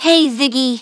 synthetic-wakewords
synthetic-wakewords / hey_ziggy /ovos-tts-plugin-deepponies_Cozy Glow_en.wav
ovos-tts-plugin-deepponies_Cozy Glow_en.wav